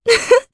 Maria-Vox-Laugh_jp.wav